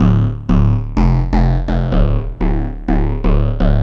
cch_bass_loop_beight_125_F.wav